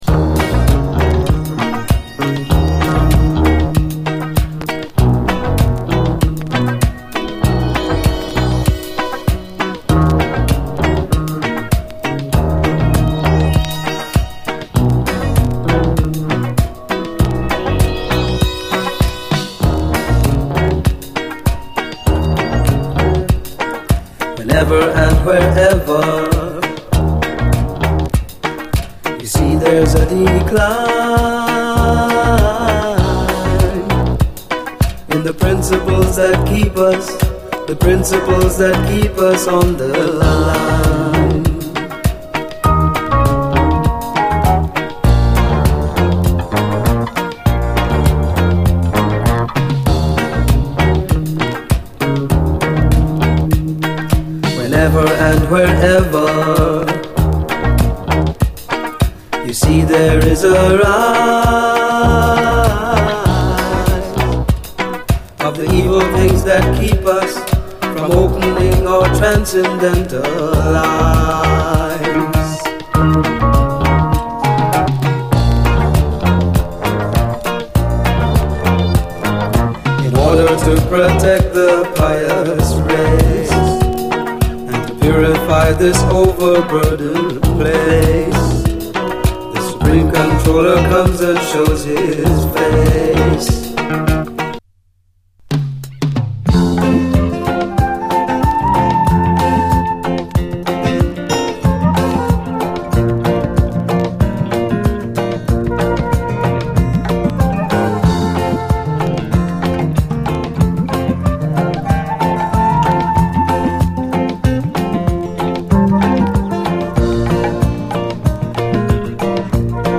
REGGAE, WORLD
イタリア産のエスニック・フェイク・レゲエ！